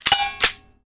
overheat.mp3